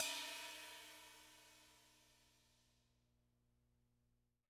R_B China 02 - Close.wav